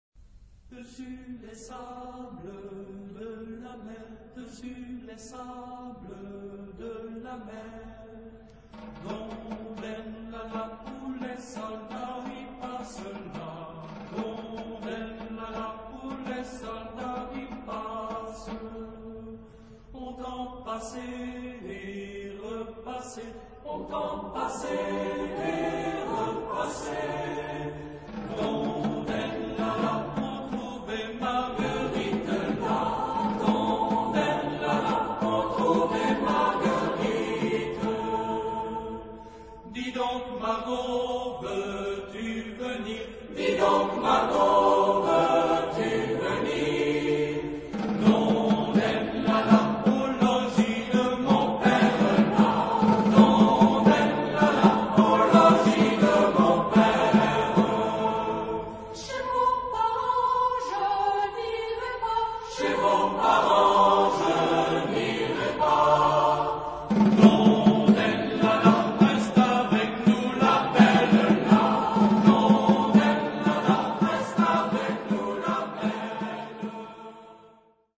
Genre-Style-Form: Folk music ; Secular ; Partsong
Type of Choir: SATB  (4 mixed voices )
Soloist(s): Soprano (1) / Ténor (1)  (2 soloist(s))
Instrumentation: Percussion  (1 instrumental part(s))
Instruments: Snare drum (1)
Tonality: A minor
Origin: Upper Brittany (France)